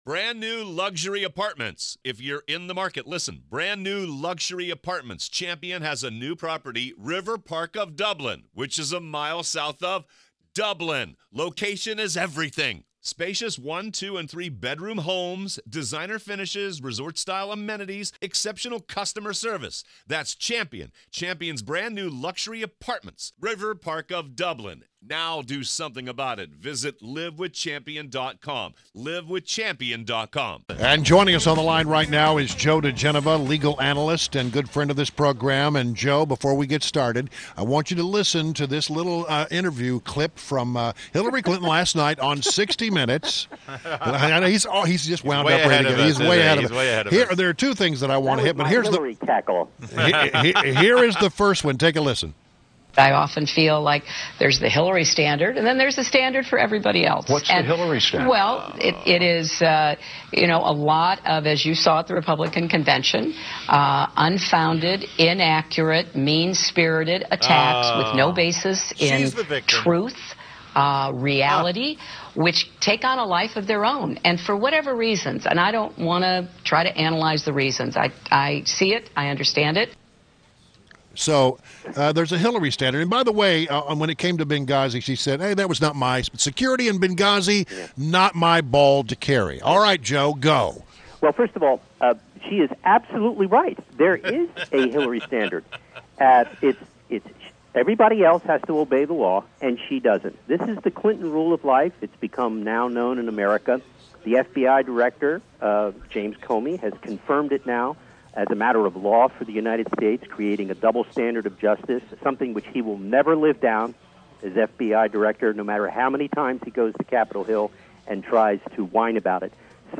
WMAL Interview - Joe Digenova- 07.25.16